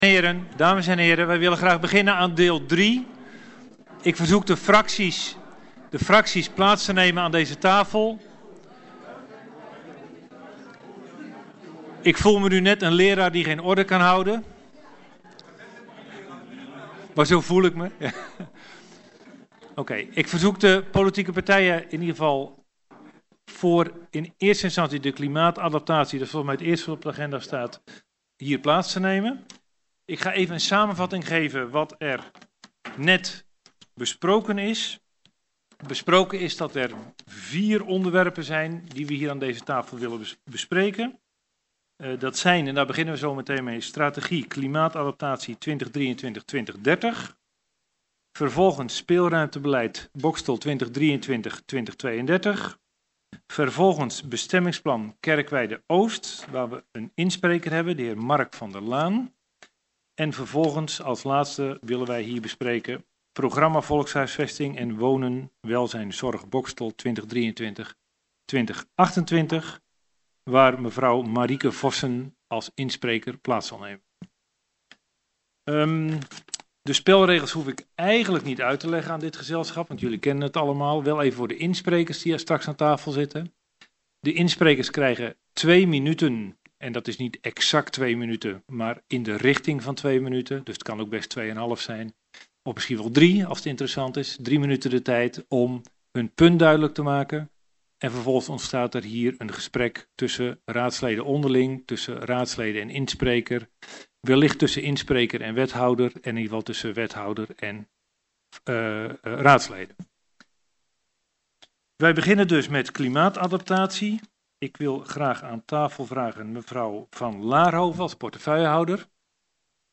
Geluidopname In gesprek onderdeel 3 gesprekstafel.MP3